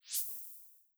LittleSwoosh2a.wav